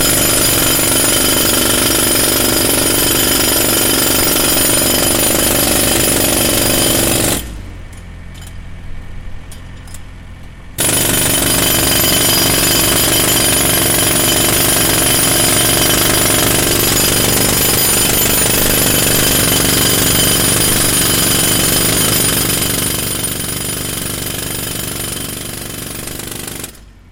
دانلود صدای هیلتی از ساعد نیوز با لینک مستقیم و کیفیت بالا
جلوه های صوتی
برچسب: دانلود آهنگ های افکت صوتی اشیاء